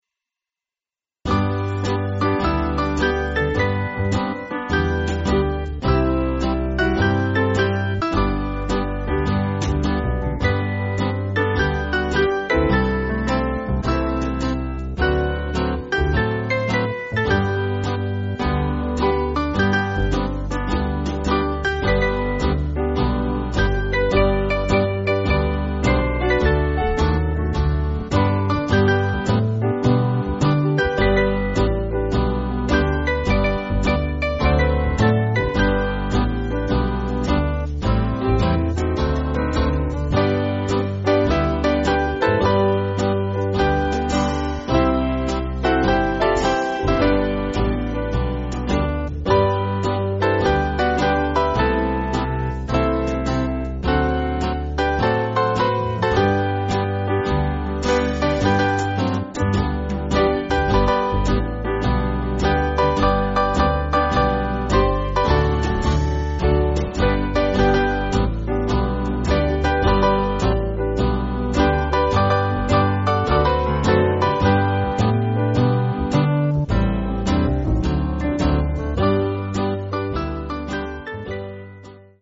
Country/Gospel